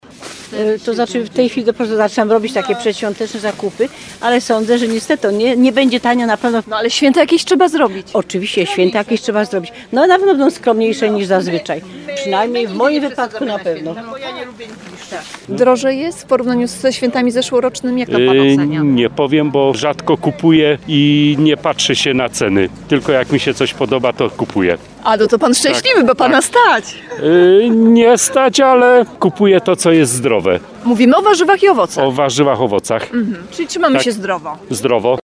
– Na pewno będą skromniejsze niż zazwyczaj – uważa jedna z kupujących.